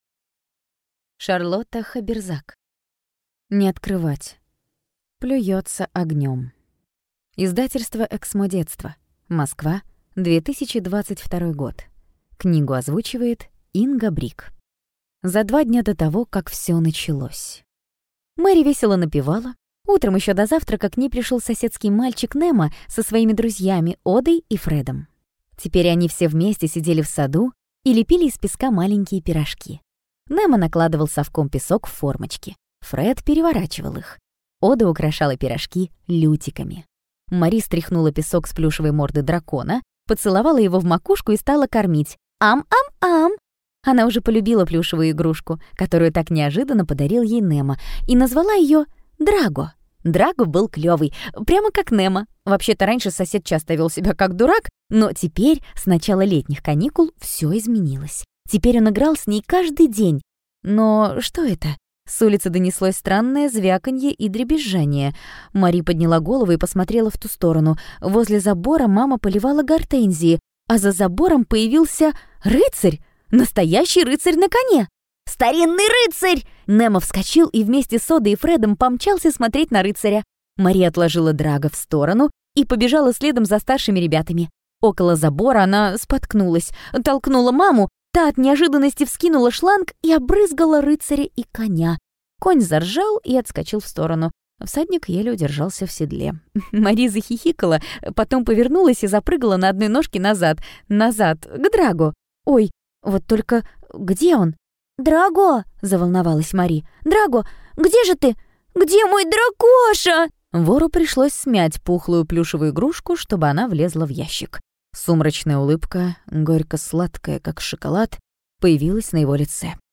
Аудиокнига Не открывать! Плюётся огнём!